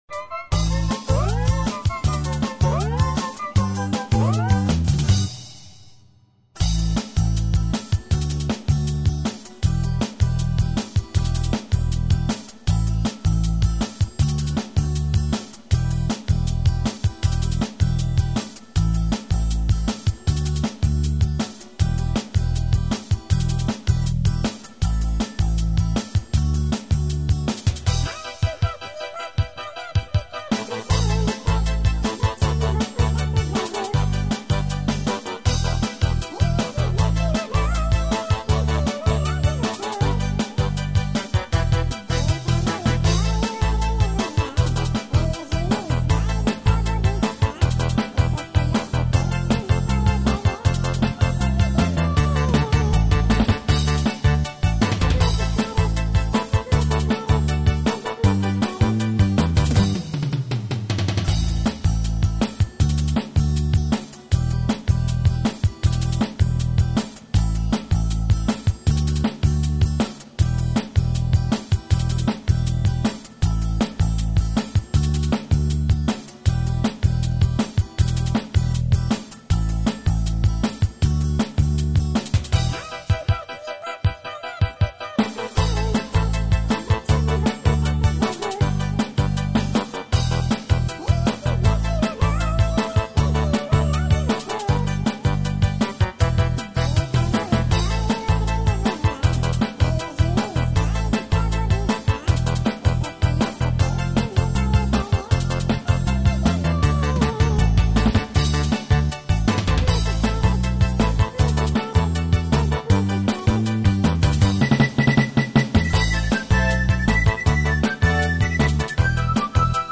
минусовка